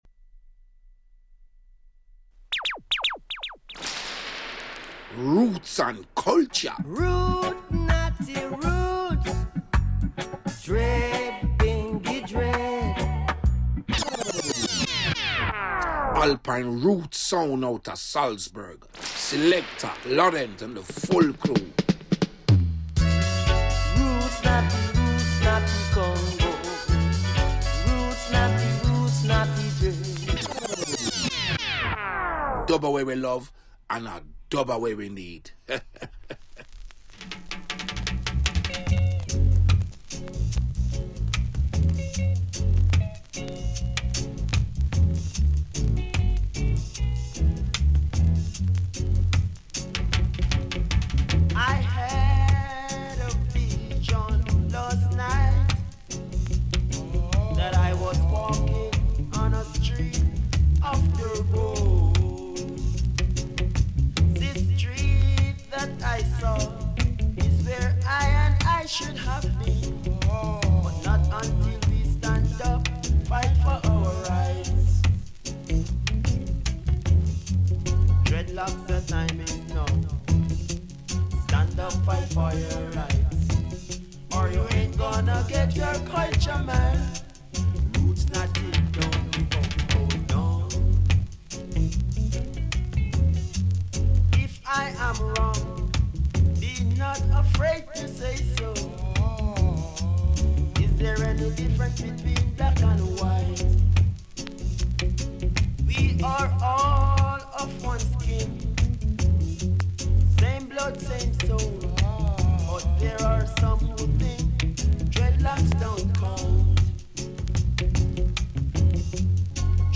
Live Radioshow